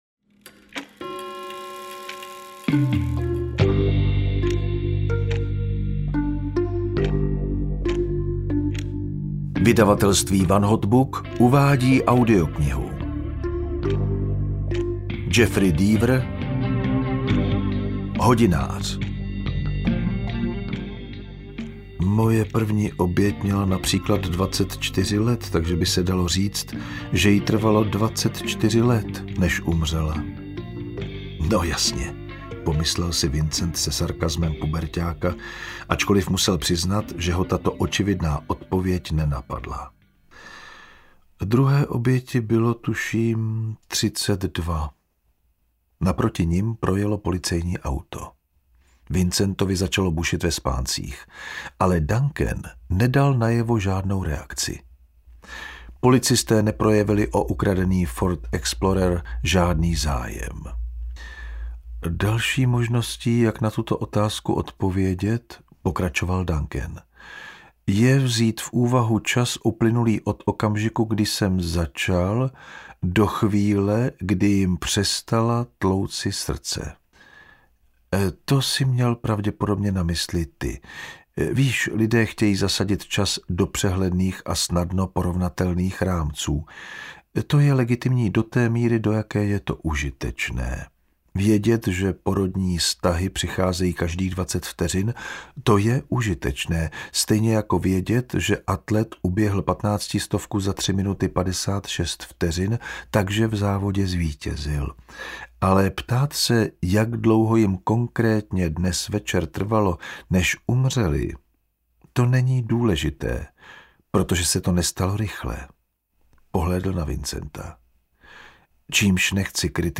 Hodinář audiokniha
Ukázka z knihy
• InterpretJan Vondráček